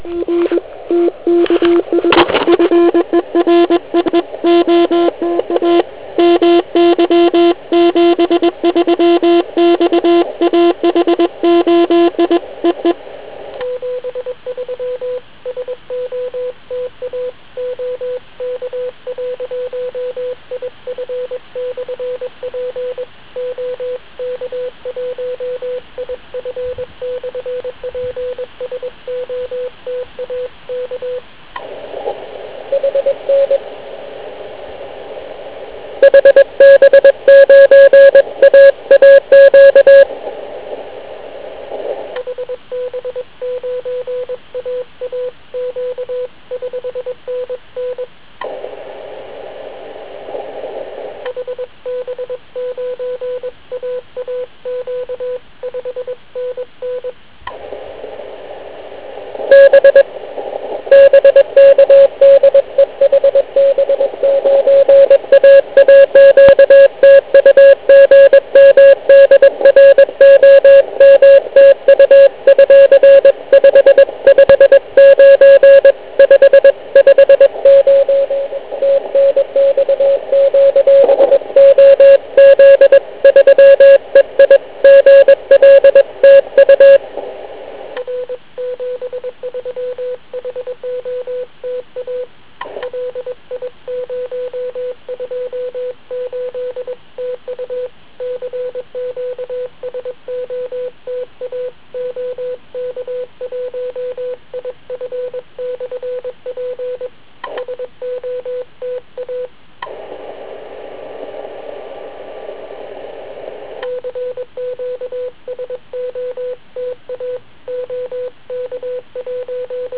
Raději jsem snížil výkon na 1W a do blbě přizpůsobené antény jsem udělal povinná 4 spojení (na kmitočtu 7032 KHz). Přesto je tam plno "lovců - chasers", kteří mne ulovili.